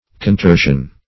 Contorsion \Con*tor"sion\, n.